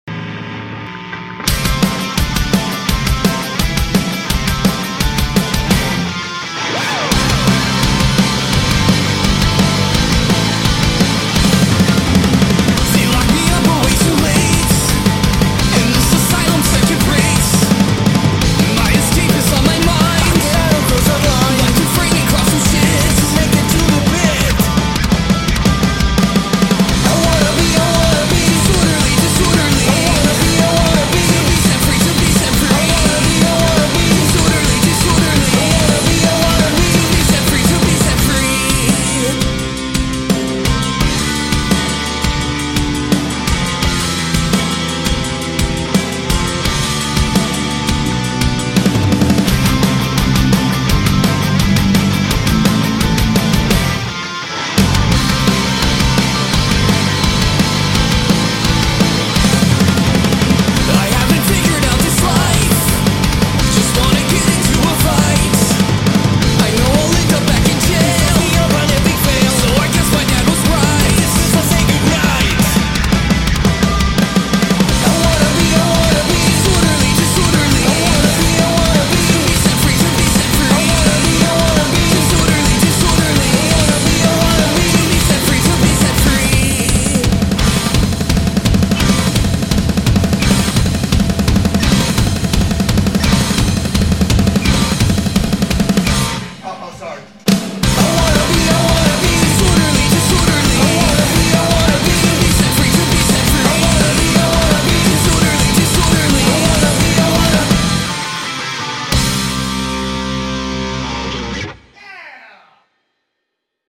vocals and guitar
bass
back-up vocals and guitar
drums and back-up vocals